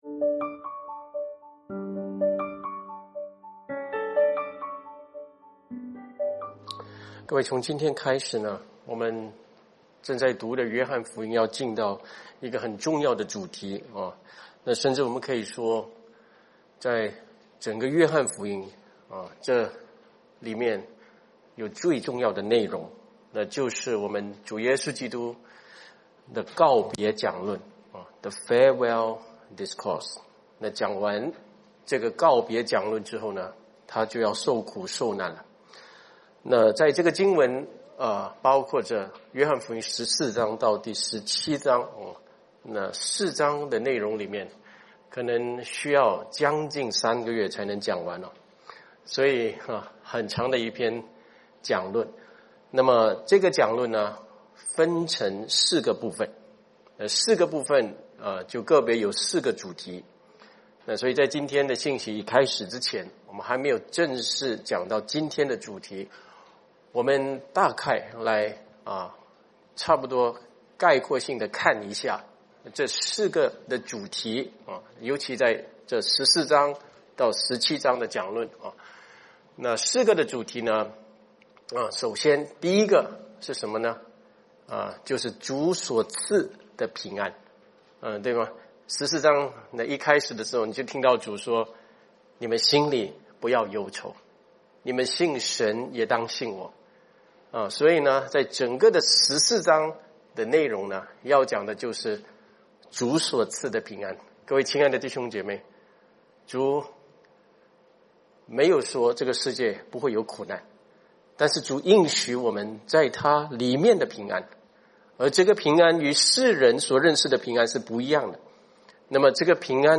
约翰福音释经讲道